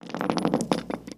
grenade_roles_in2.wav